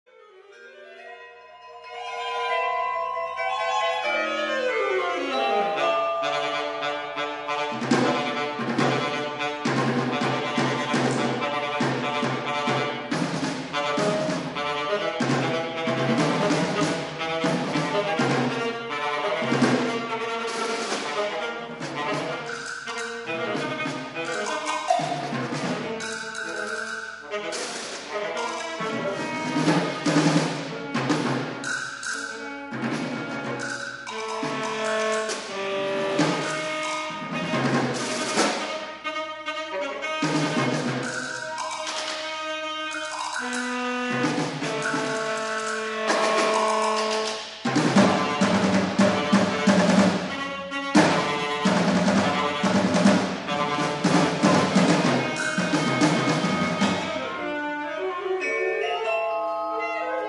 for Alto Saxophone
and Percussion